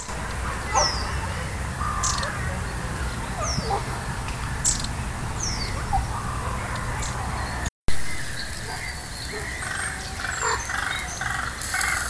Птицы -> Синицевые -> 1
длиннахвостая синица, Aegithalos caudatus
СтатусПара в подходящем для гнездования биотопе